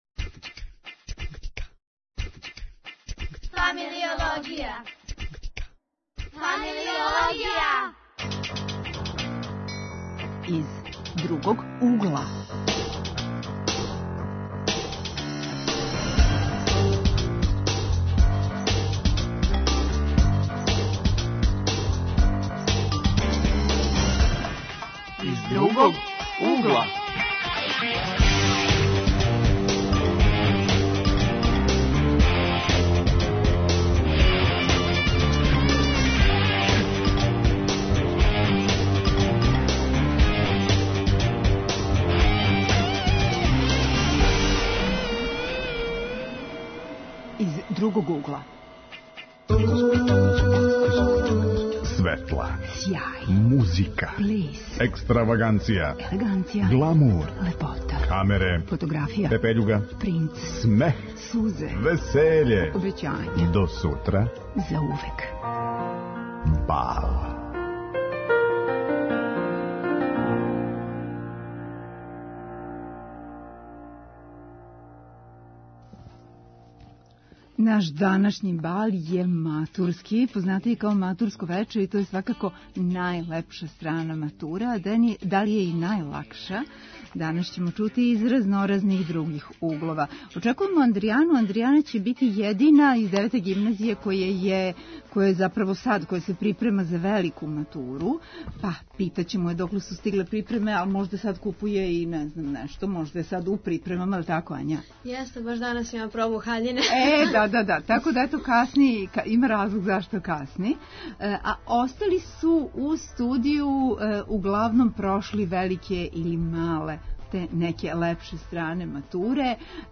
Глас савести: приче бивших матураната - како не погрешити.